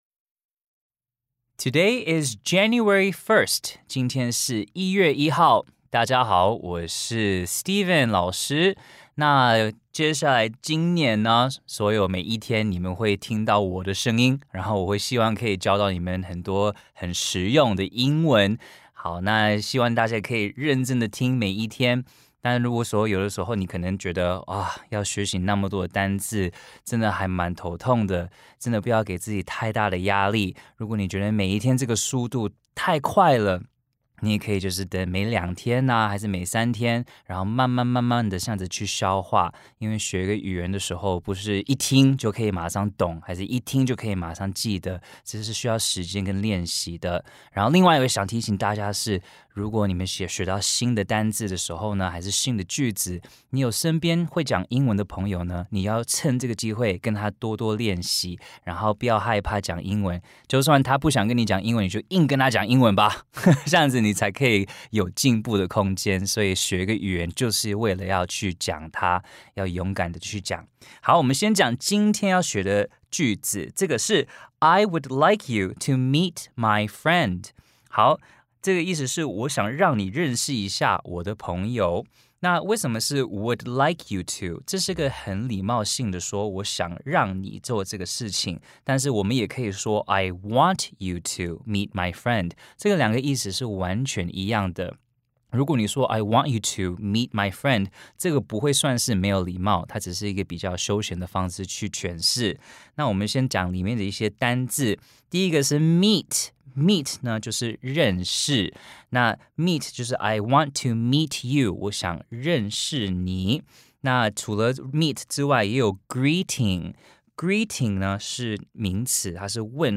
每日一句玩樂英語，輕輕鬆鬆就上口。道地華裔美籍老師，朗讀單字講解句子，帶你深入美國人的日常生活與文化。